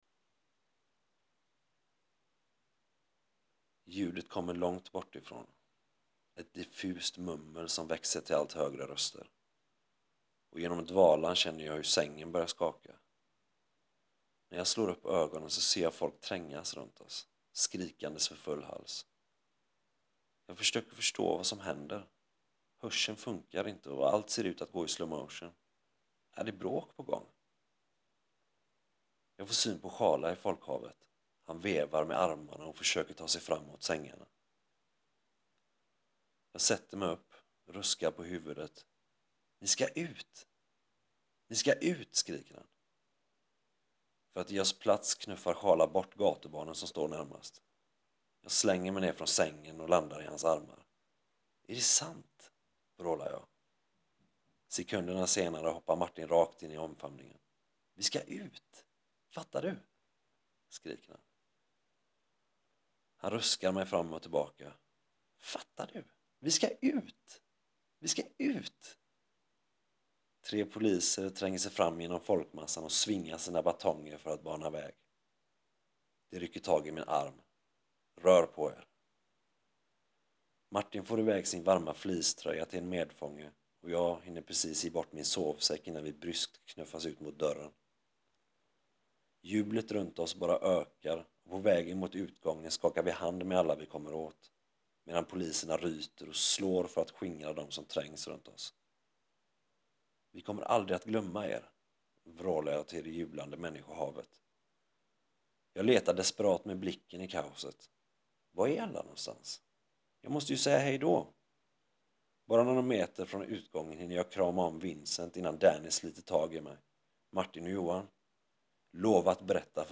RÖST